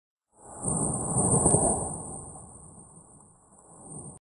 地球物理をやる学科に居るので、地震も学んでいるのですが、ふと思いついたので地震計の観測した波形を音に見立ててWAVファイルにしてみました。
なんだか、雷みたいな音がしますね！